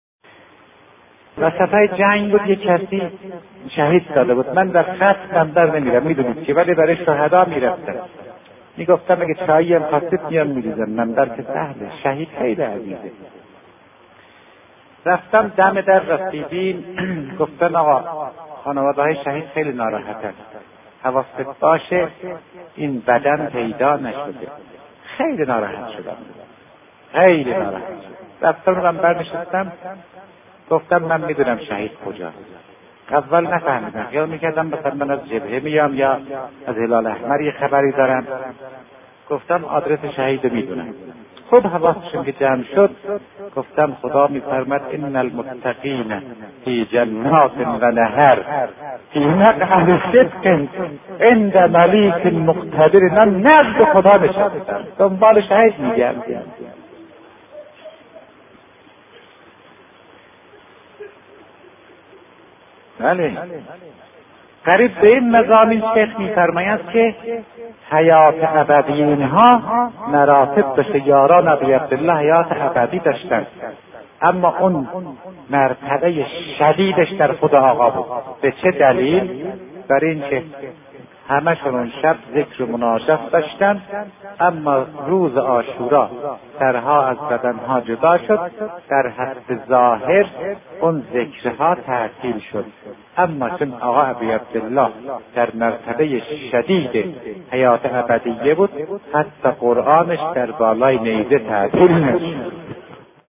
مجموعه پادکست «روایت بندگی» با کلام اساتید بنام اخلاق به کوشش ایکنا گردآوری و تهیه شده است که دهمین قسمت این مجموعه با کلام آیت‌الله سید عبدالله فاطمی‌نیا(ره) با عنوان «جایگاه شهدا نزد پروردگار» تقدیم مخاطبان گرامی ایکنا می‌شود.